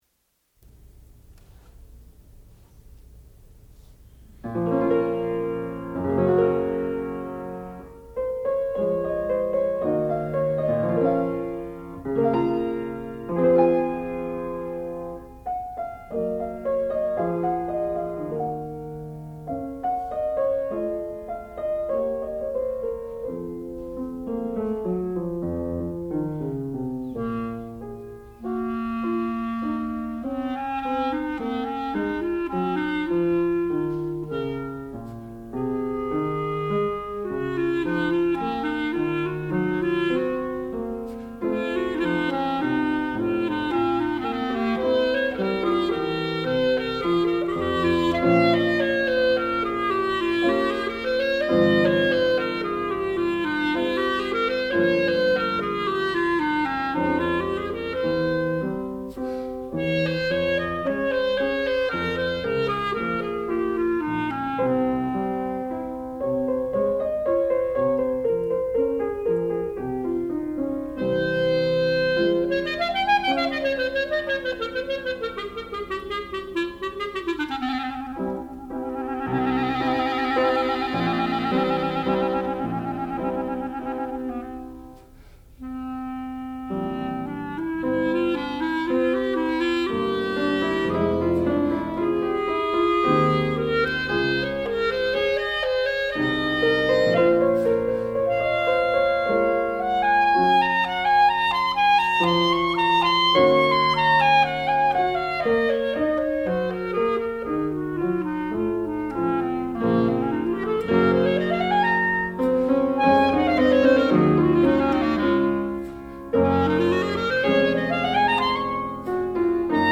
sound recording-musical
classical music
piano
clarinet
Graduate Recital